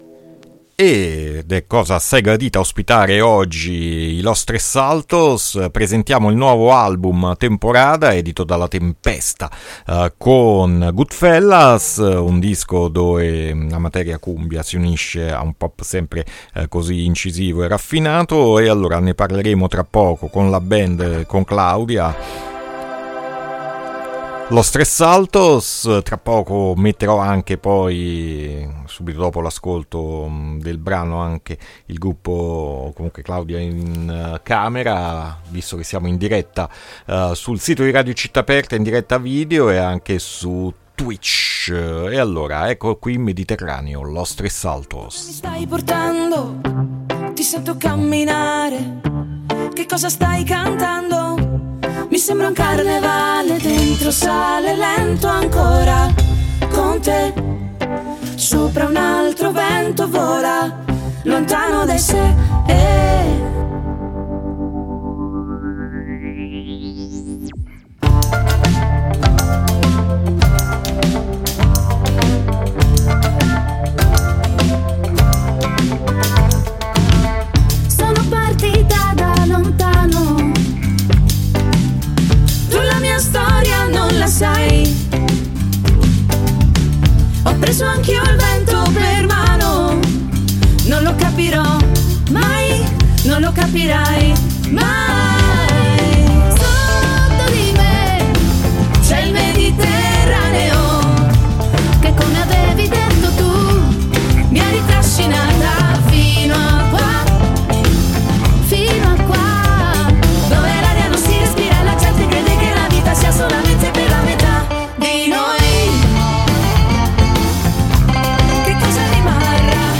INTERVISTA LOS3SALTOS AD ALTERNITALIA 5-5-2023